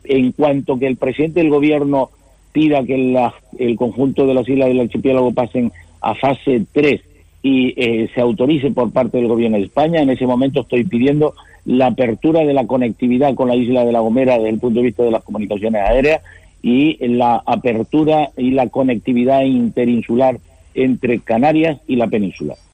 Curbelo pide reactivar los vuelos con seguridad para mover la economía